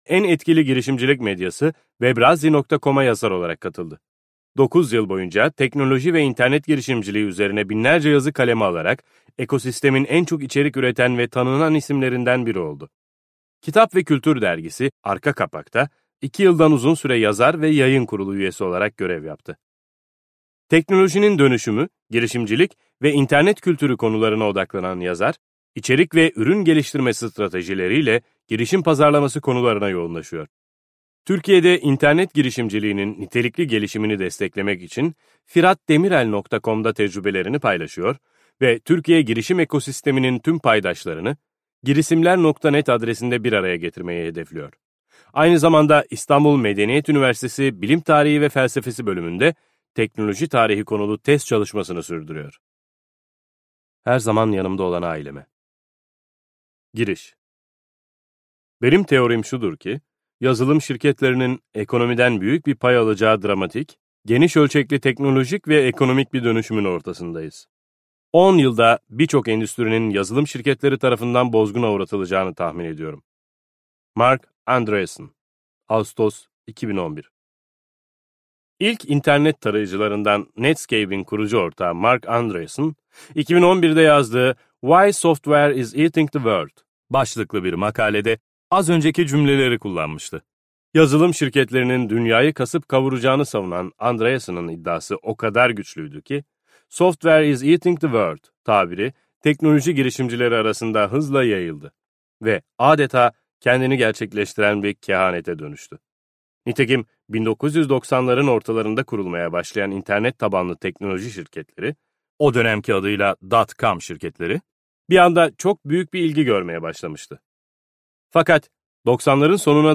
Geleceği Görenler - Seslenen Kitap